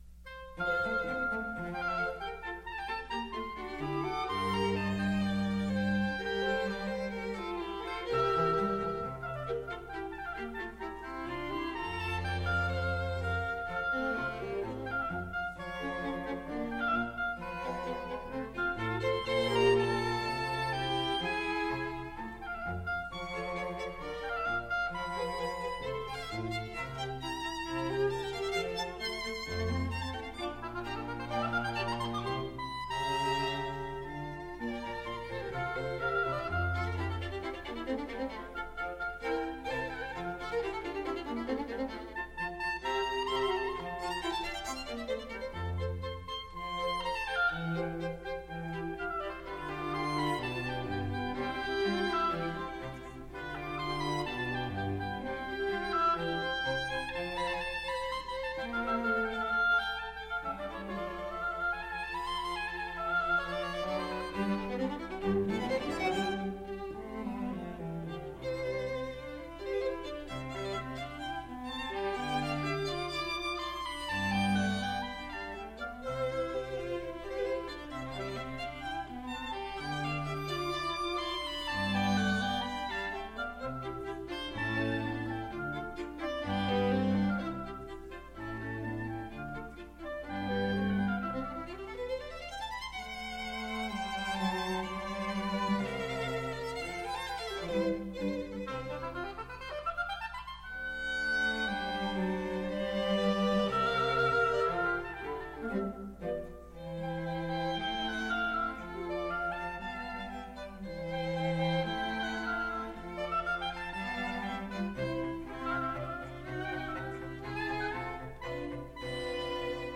Oboe and Ensemble
Style: Classical
violin
viola